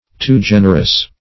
too-generous - definition of too-generous - synonyms, pronunciation, spelling from Free Dictionary